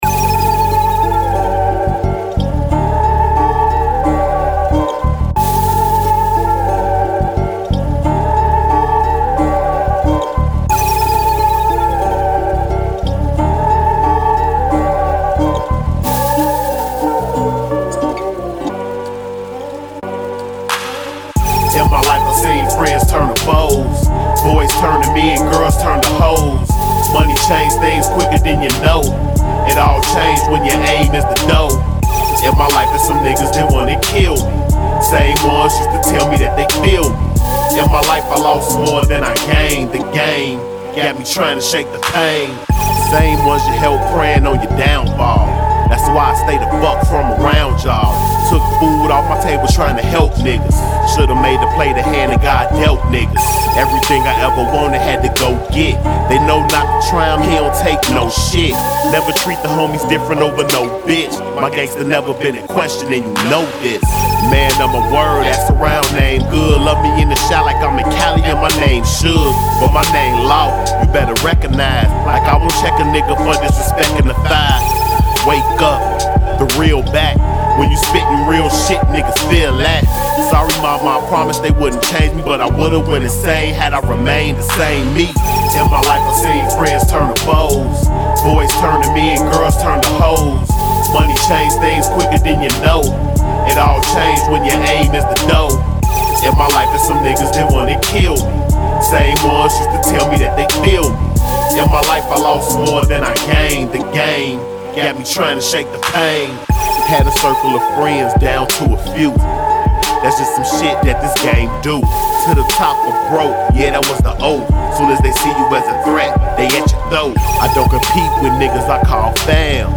reflective track